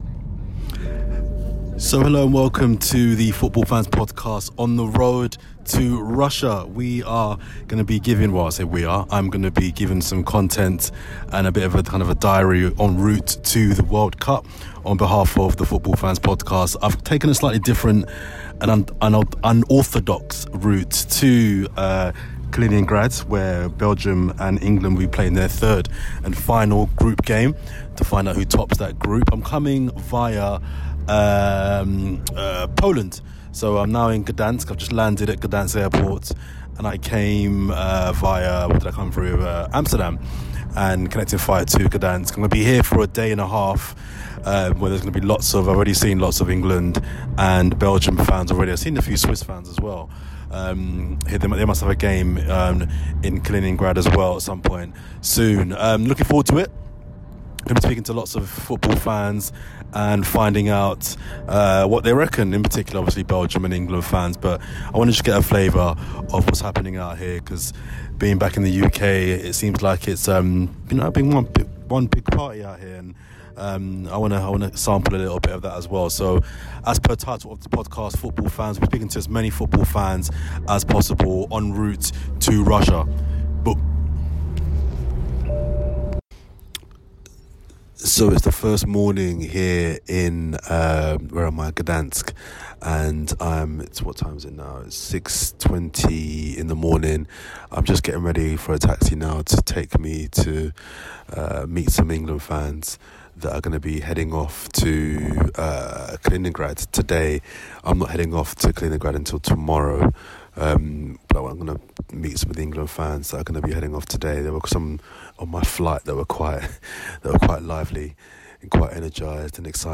A special podcast with me heading to Russia for the World Cup clash between England and Belgium. I travelled to Kaliningrad via Gdansk, Poland and Amsterdam, Holland with England fans on bus and plane. I spoke to fans heading to the game as well as Polish cab drivers and more.